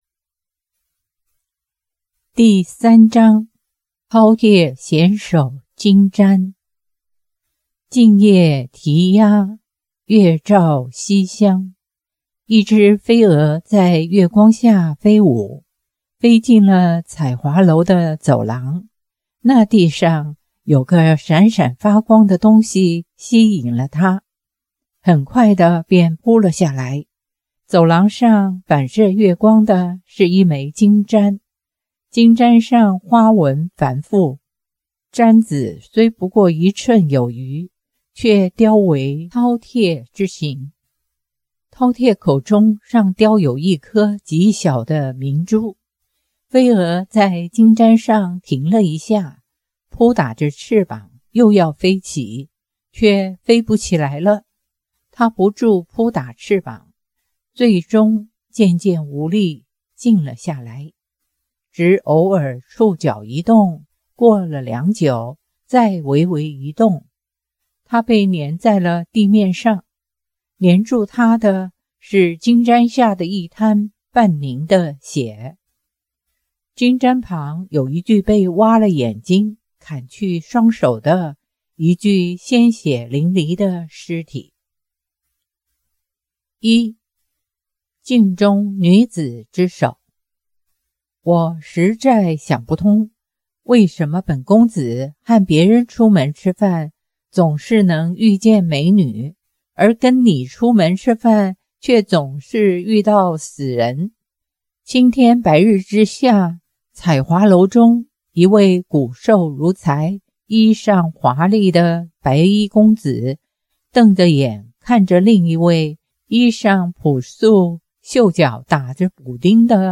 好讀新有聲書